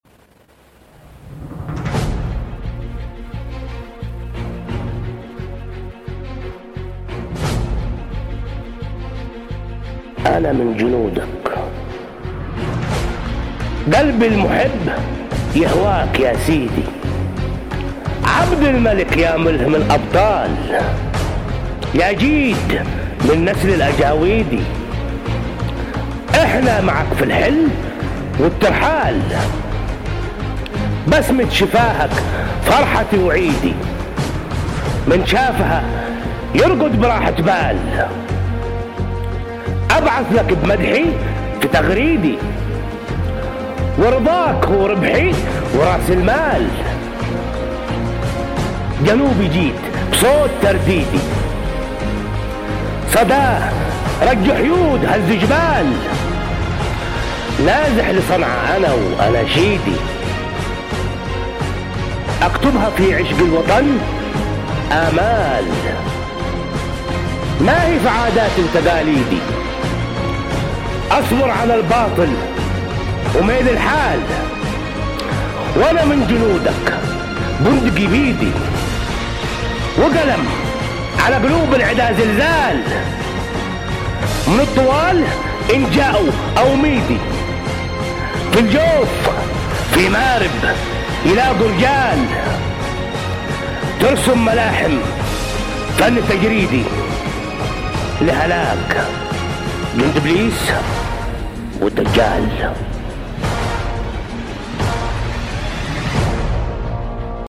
قصيدة : أنا من جنودك